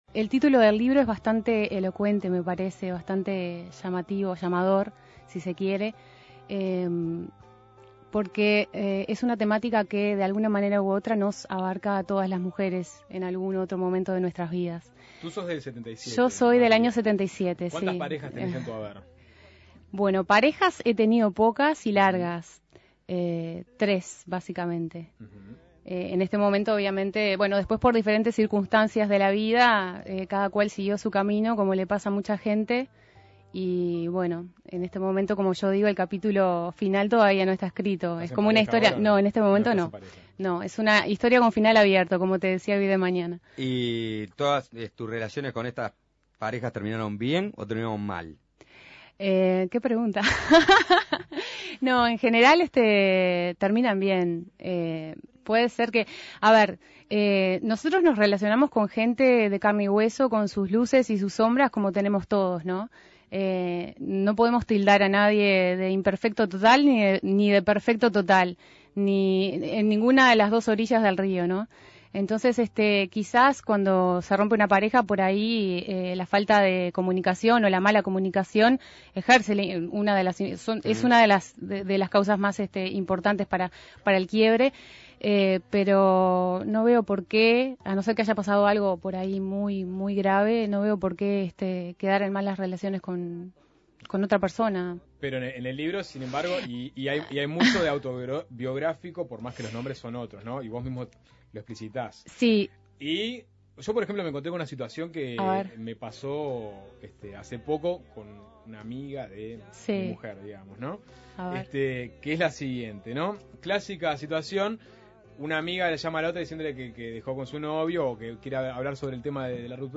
Suena Tremendo Entrevista